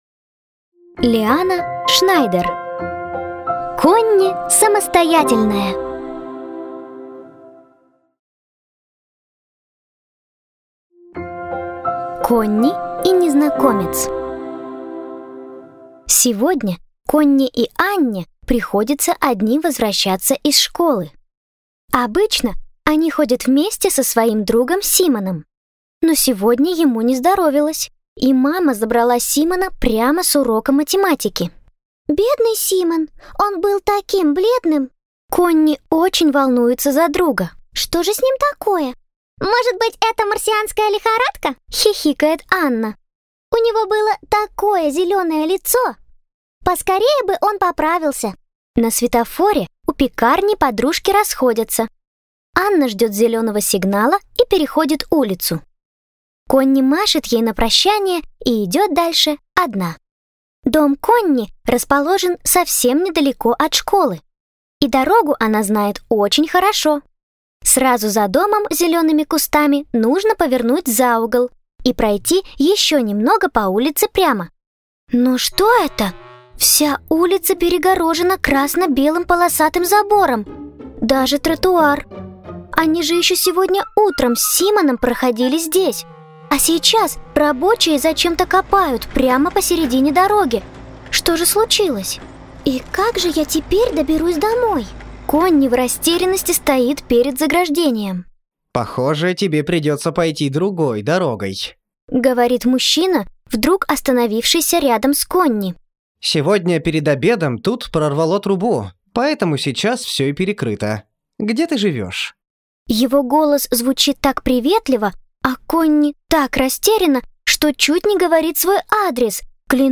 Аудиокнига Конни – самостоятельная. Сборник историй | Библиотека аудиокниг